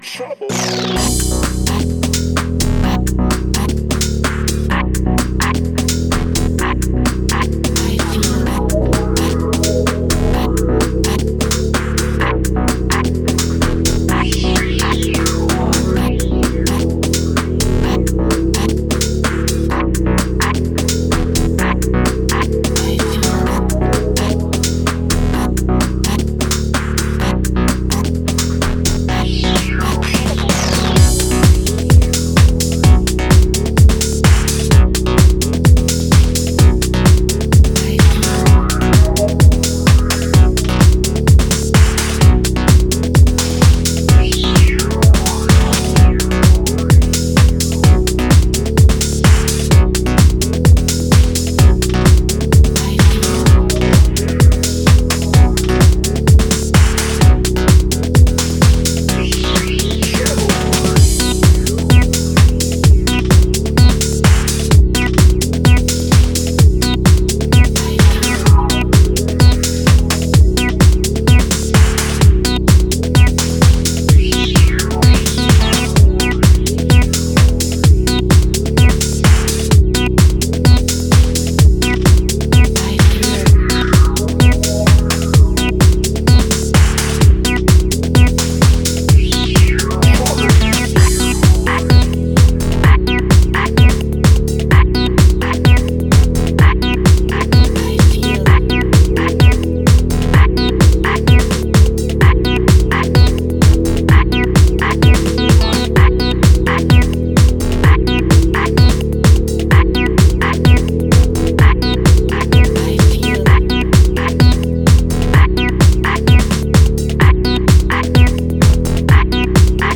ベースラインがブーストしグルーヴを形どる即効性抜群のモダン・ミニマル